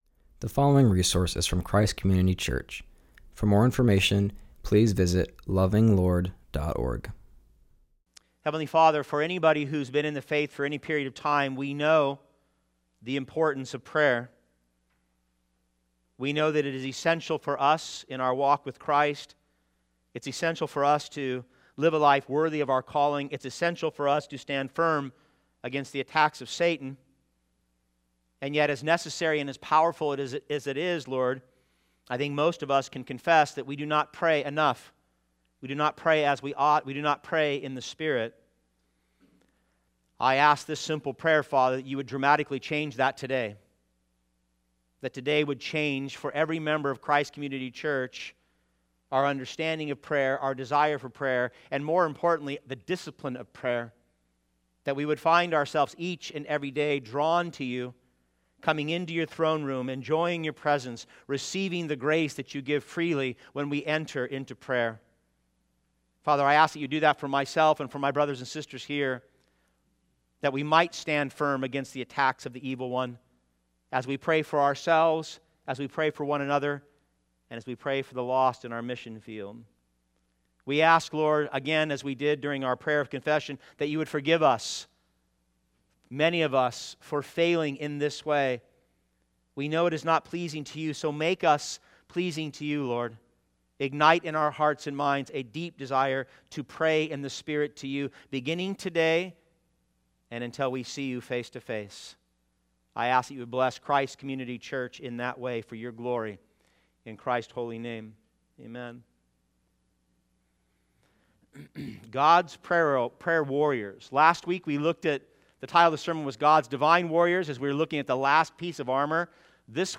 continues our series and preaches from Ephesians 6:18-20.